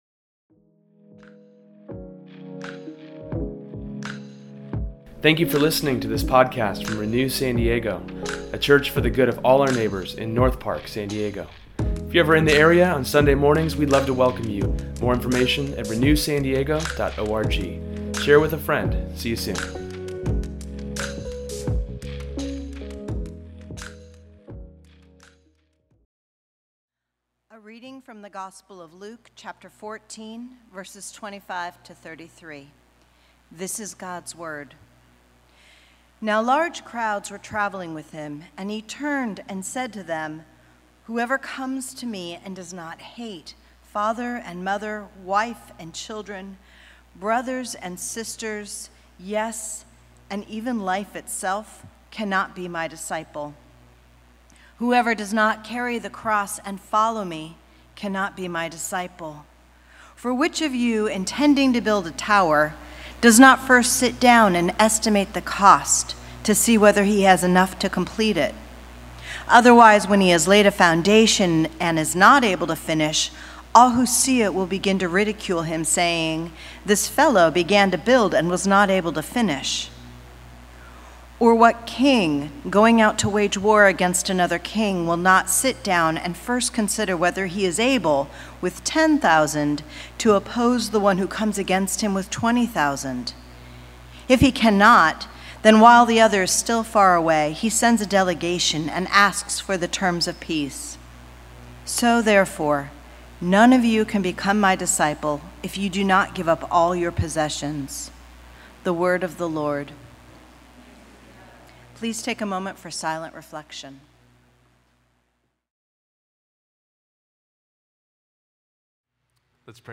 In today’s sermon, we explore the cost of following Jesus, but how it leads to your freedom.